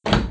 door_open.wav